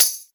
Santeria Tamborine.wav